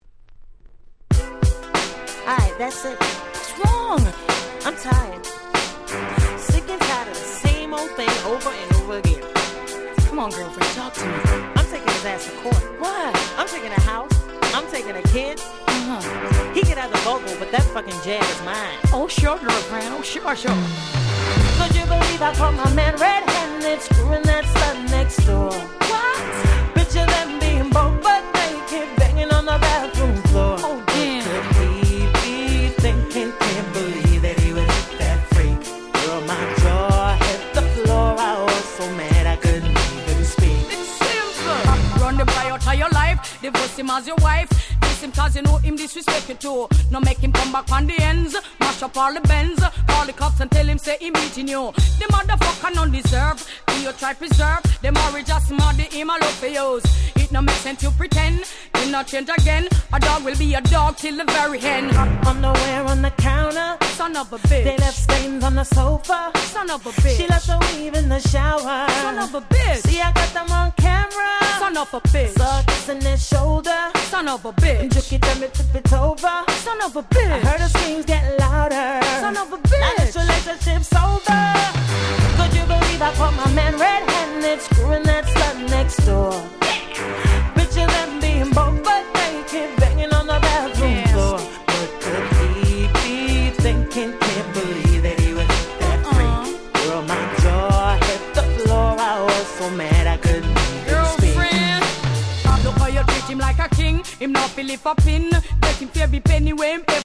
01' Super Hit Reggae !!